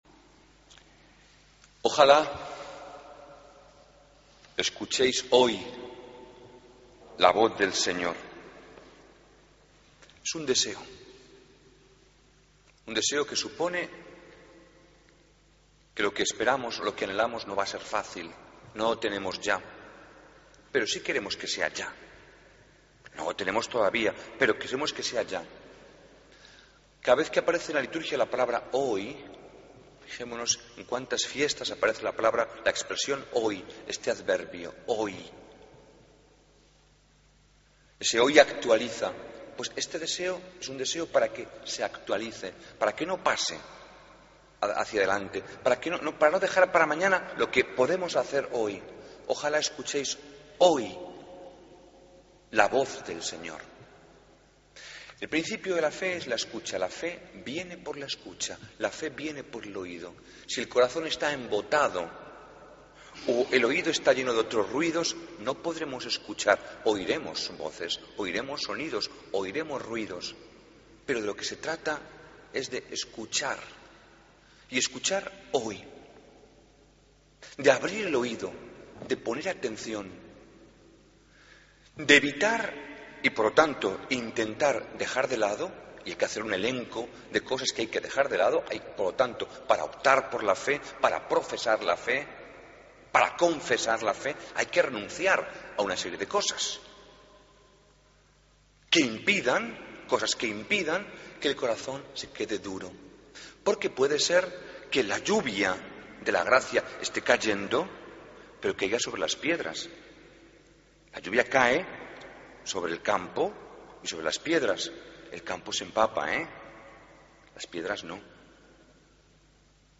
Homilía del 5 de Octubre de 2013